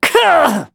voices / heroes / en
Kibera-Vox_Damage_03.wav